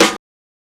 SNARE NAIL.wav